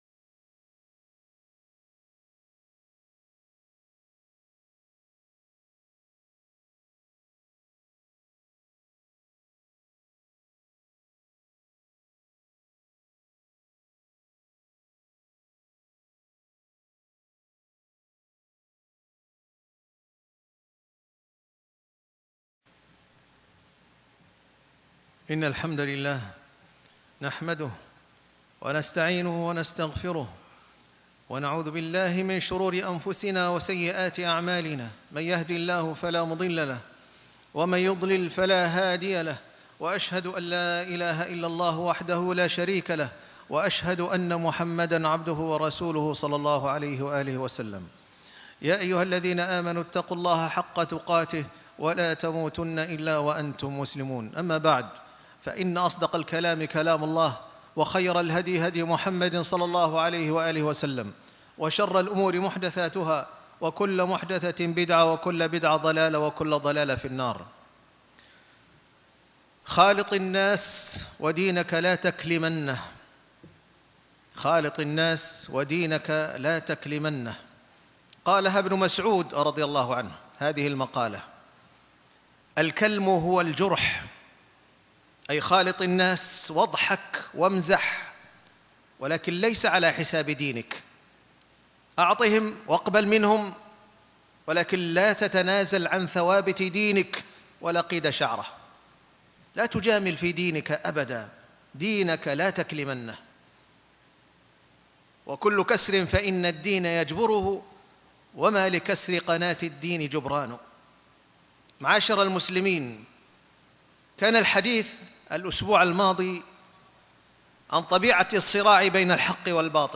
ودينك لاتكلمنّه -الجزء الثاني - خطبة الجمعة